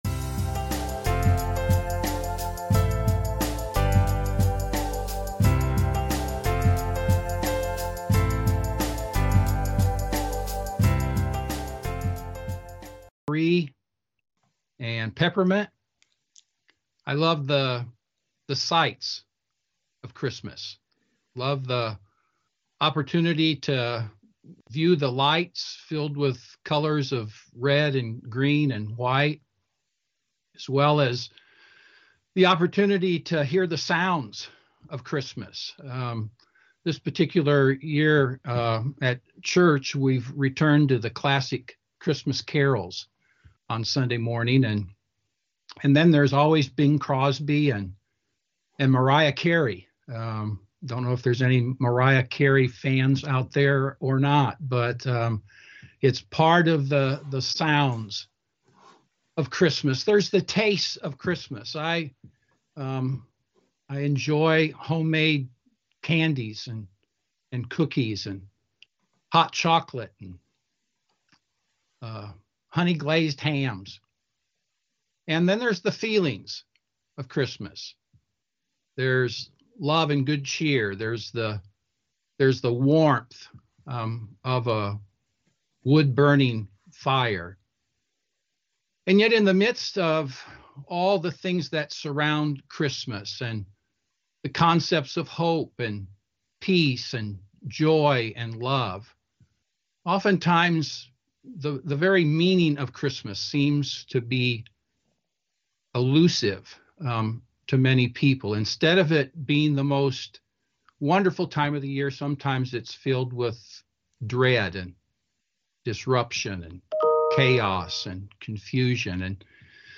NBC Audio Chapel Services